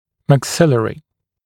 [mæk’sɪlərɪ][мэк’силэри]верхнечелюстной